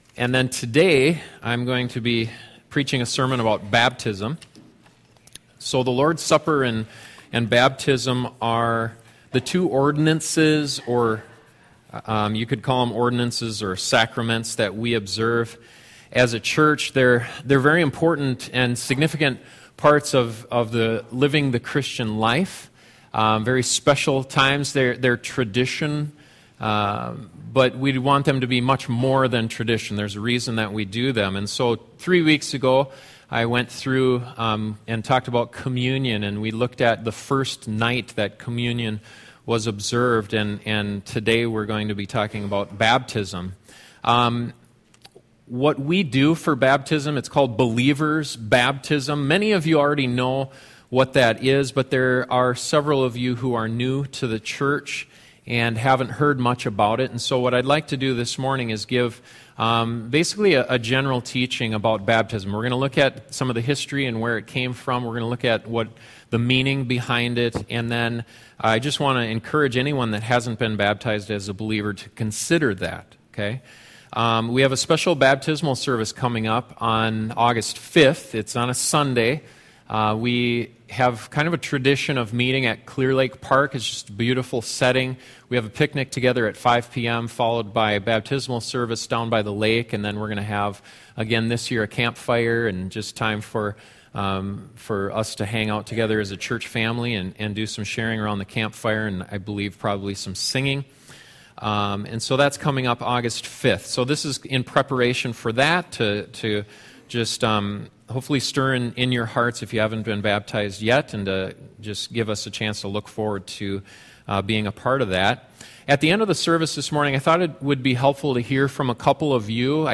This sermon looks at some of the historical background behind believers’ baptism, what it means, and who should get baptized.